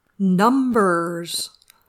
Numbers.mp3